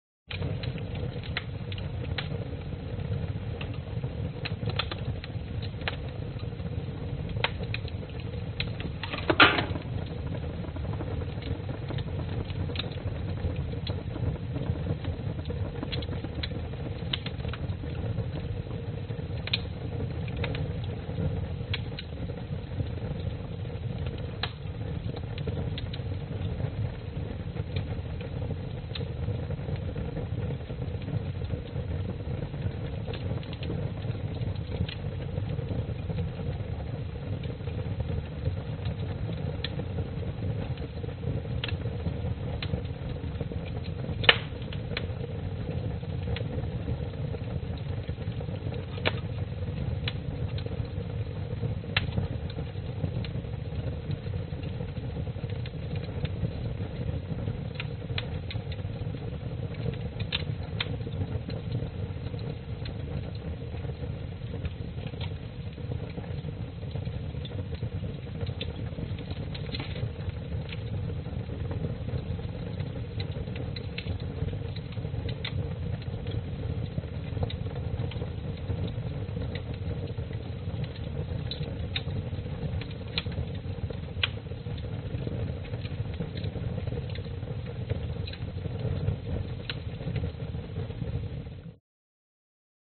描述：壁炉燃烧橄榄树木柴，树液沸腾，噼啪作响。森海塞尔MKH60 + MKh30进入舒尔FP24前置放大器，奥林巴斯LS10录音机
标签： 烟囱 噼里啪啦 现场录音 壁炉 木柴 橄榄树
声道立体声